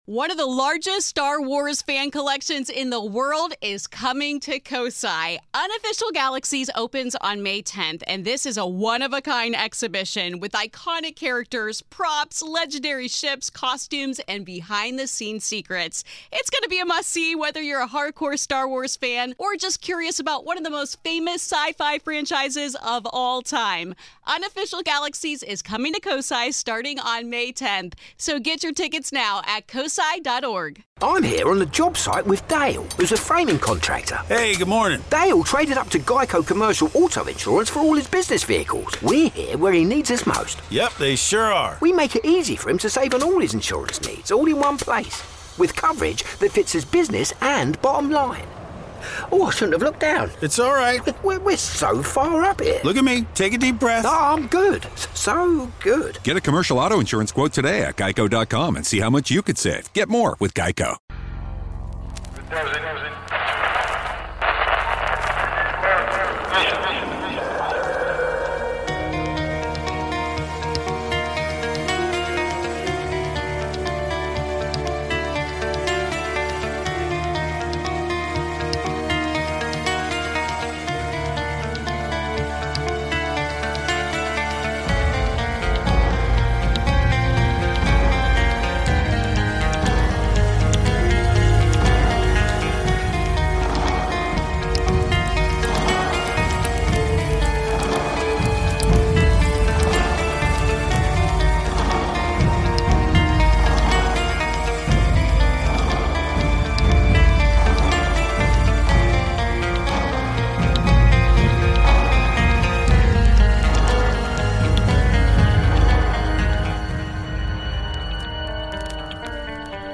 Tonight we hear calls about family ghosts, a UFO, a phantom plane crash and what might have been bigfoot.